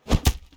Close Combat Attack Sound 22.wav